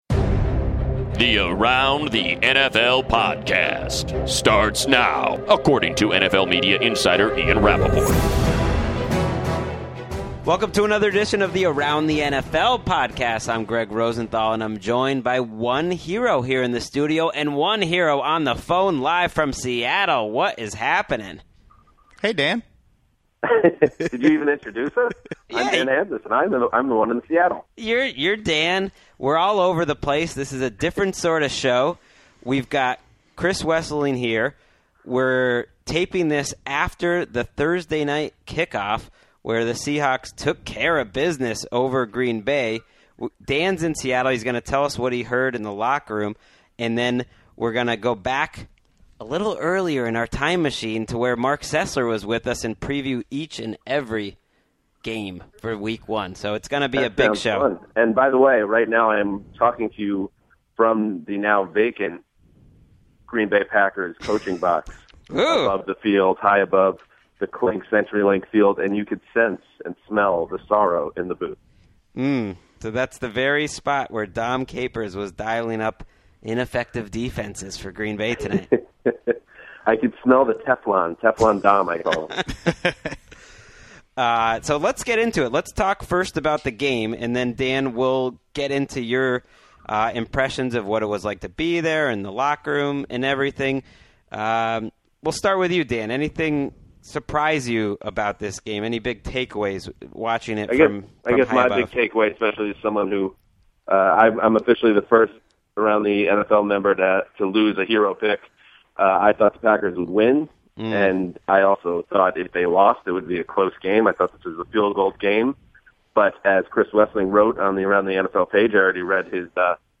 in studio
live from Seattle